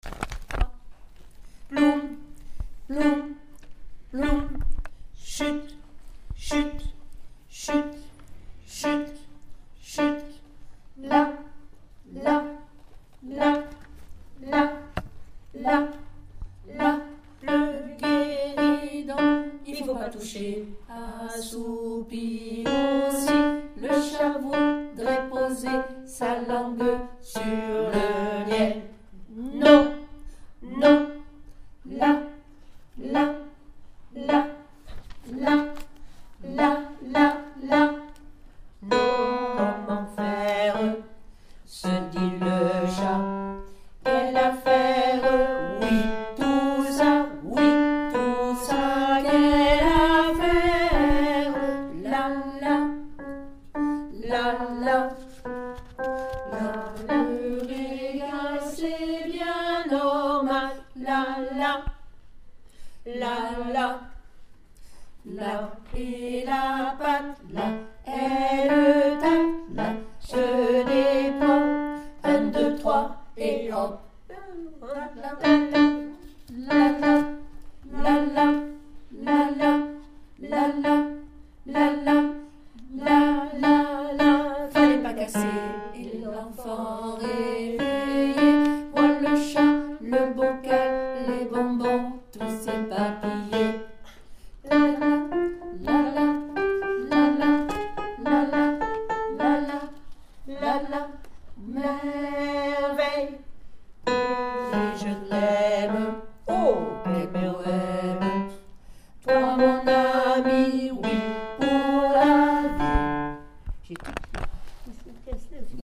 Enregistrement ALTO2